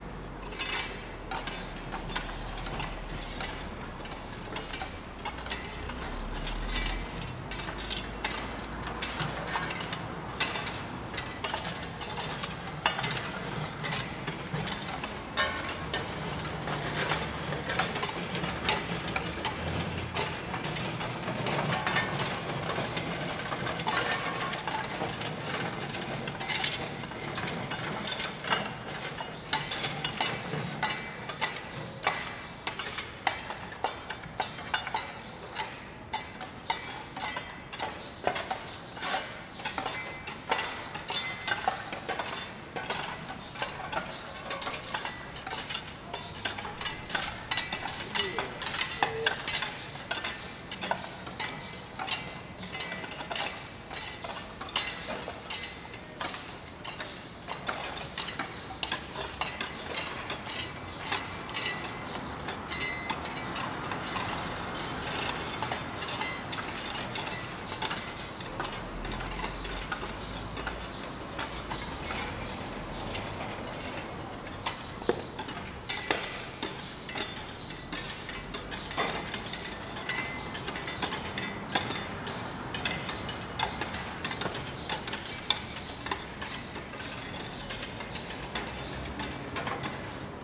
Ambiente interior apartamento noite vozerio e risadas de vizinhos Ambiente interno , Apartamento , Carro , Noite , Prédio , Residencial tranquilo , Vozerio Brasília , Plano Piloto Stereo